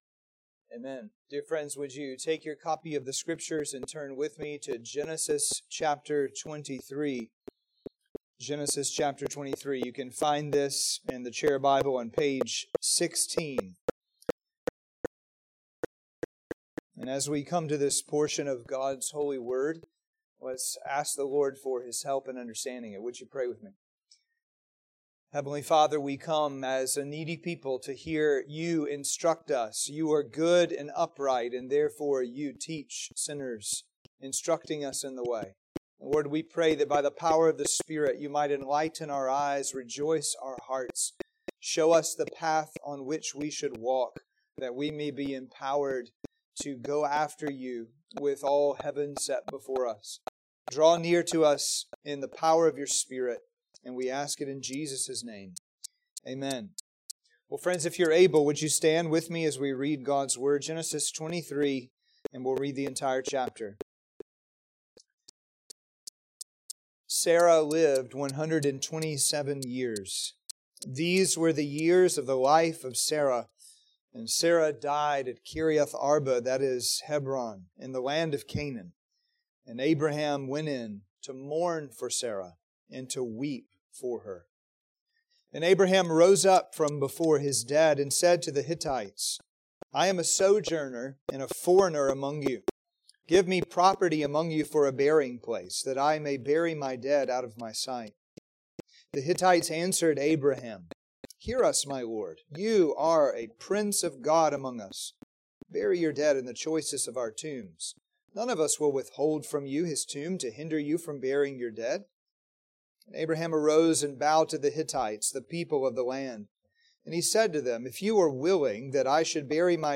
Sermons and Adult Sunday School from Grace Presbyterian Church, Douglasville, Georgia